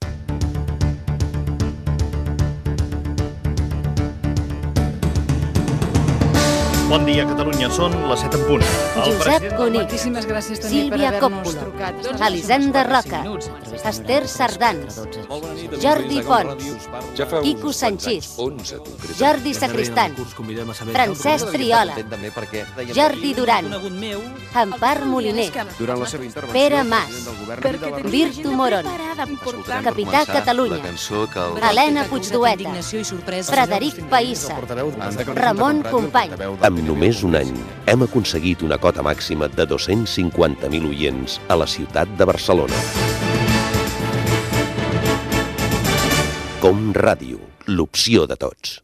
Promoció amb els noms dels principals comunicadors de COM Ràdio i l'eslògan "L'opció de tots"
Fragment extret de l'arxiu sonor de COM Ràdio